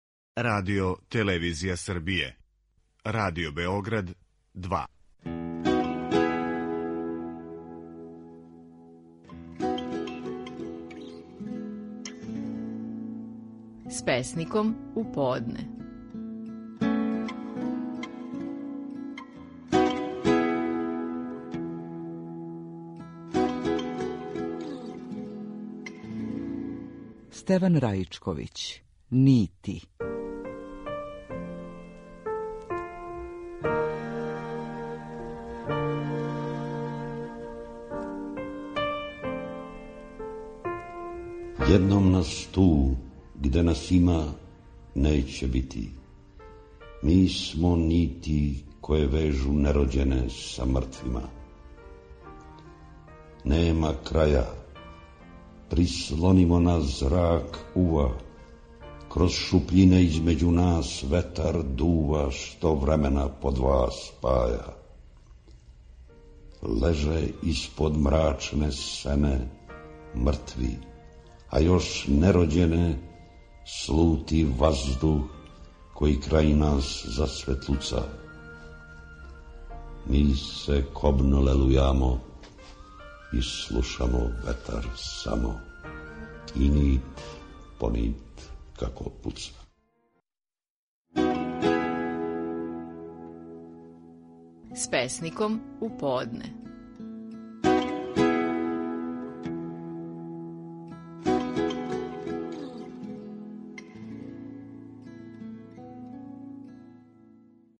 Стихови наших најпознатијих песника, у интерпретацији аутора.
Стеван Раичковић говори своју чувену песму „Нити".